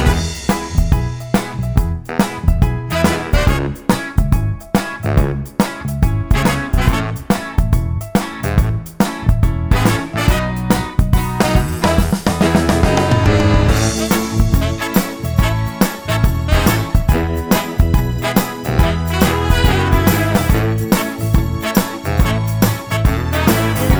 no Backing Vocals Soundtracks 3:10 Buy £1.50